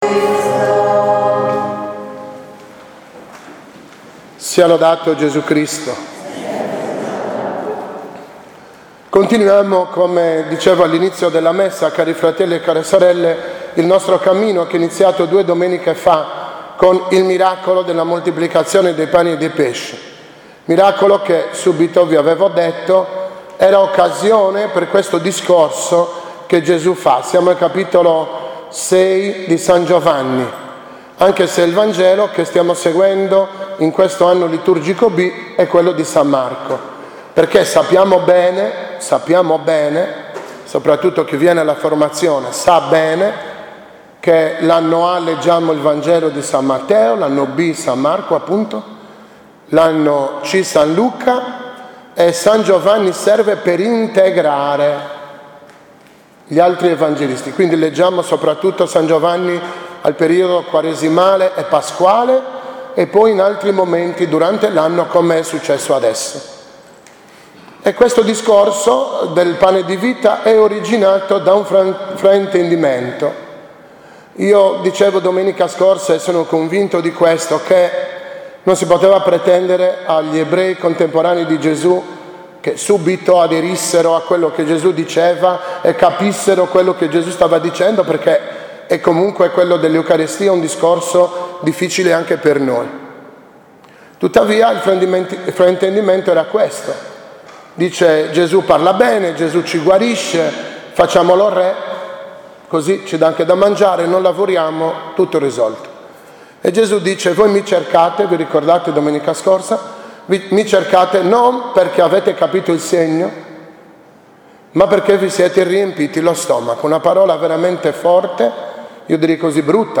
OMELIA-XIX-DOMENICA-PER-ANNUM-B-1.mp3